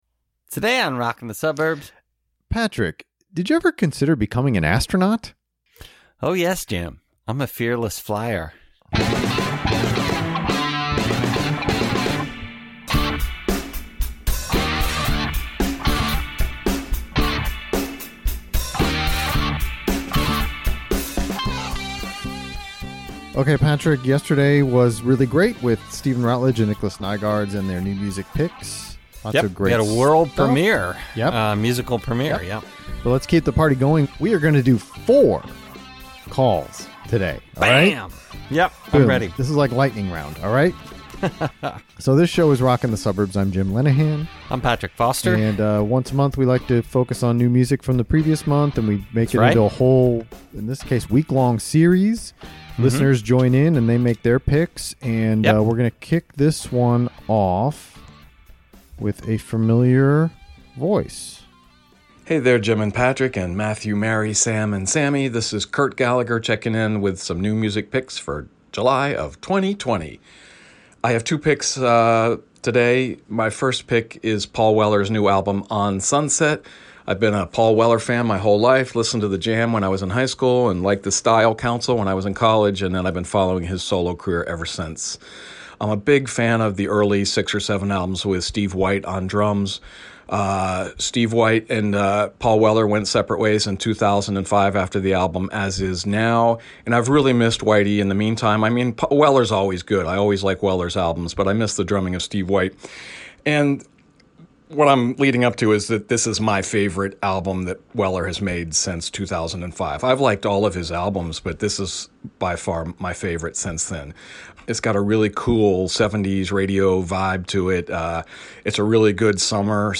Part two of our July new music listener series.